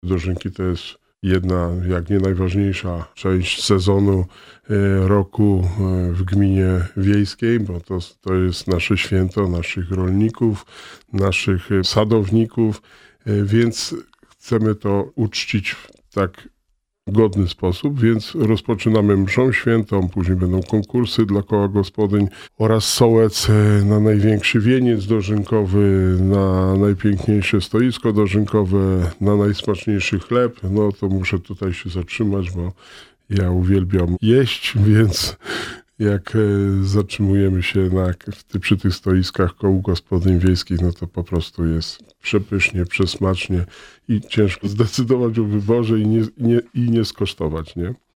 – Tradycyjnie będzie korowód dożynkowy – dodaje Wojciech Błoński.